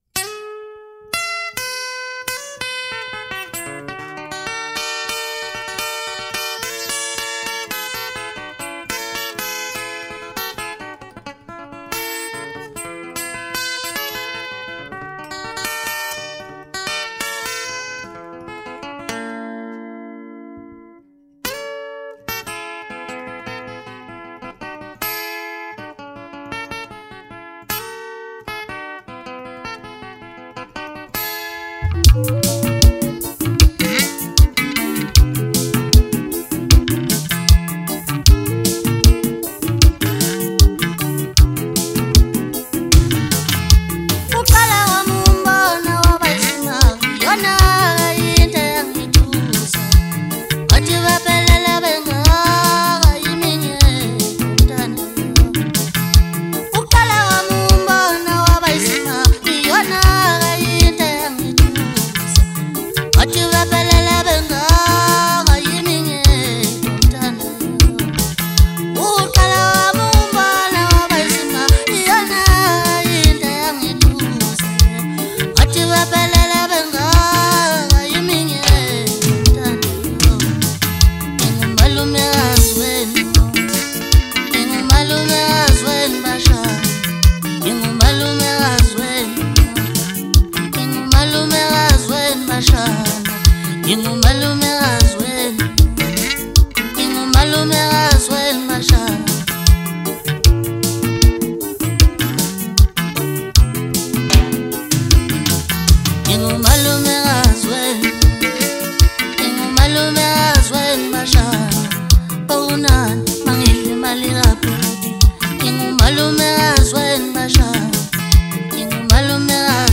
MASKANDI MUSIC
hit maskandi song